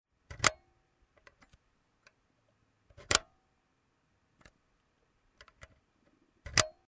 0156_Blendeneinstellung.mp3